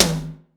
ROOM TOM1A.wav